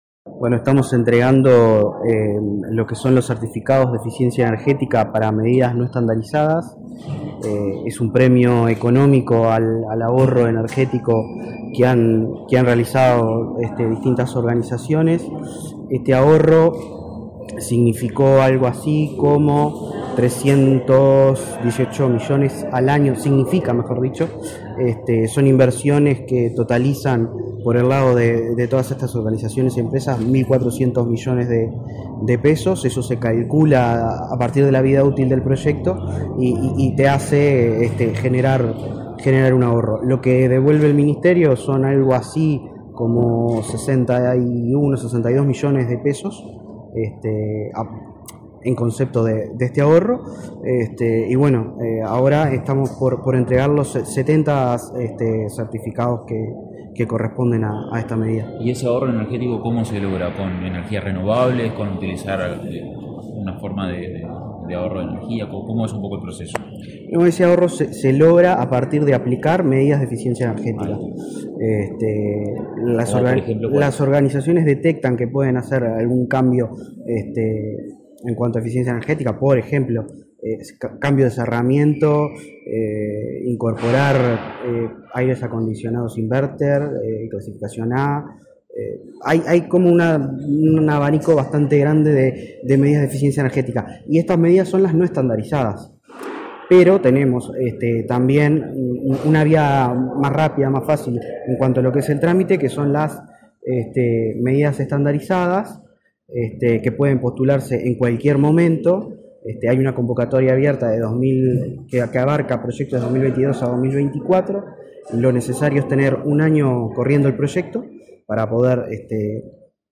Declaraciones del director nacional de Energía, Christian Nieves